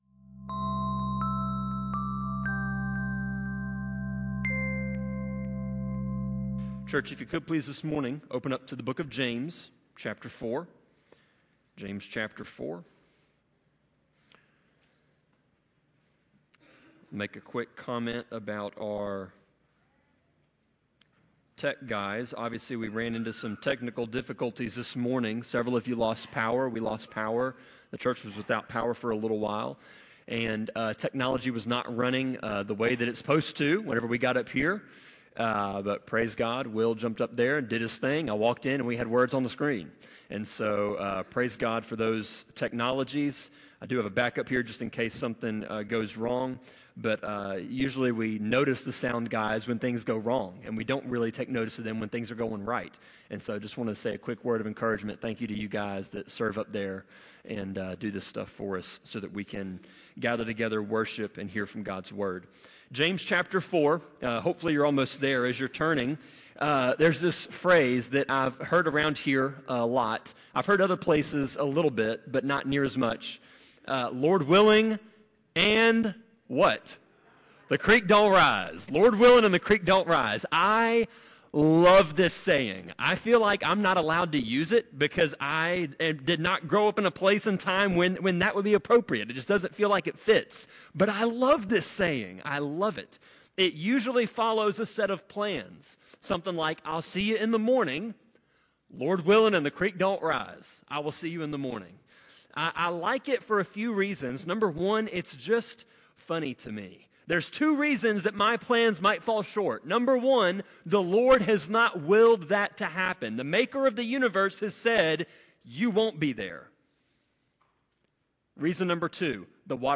Sermon-25.7.27-CD.mp3